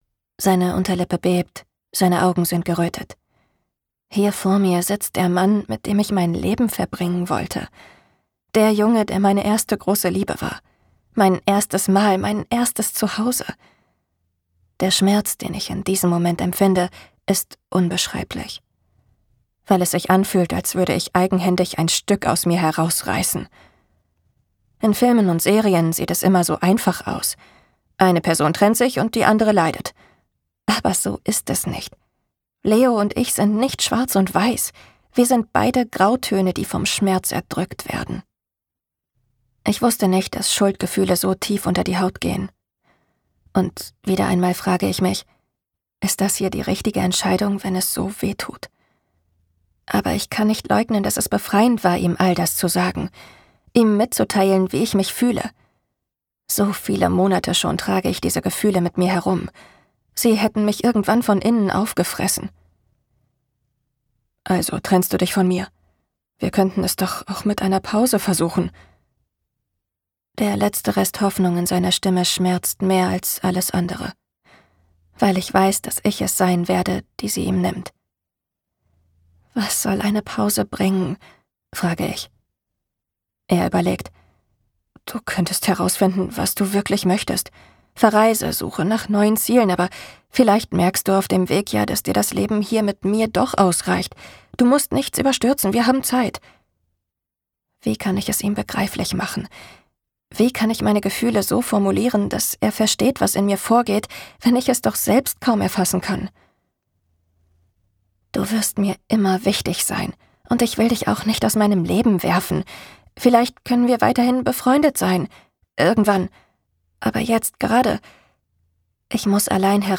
plakativ, sehr variabel
Jung (18-30)
Audio Drama (Hörspiel)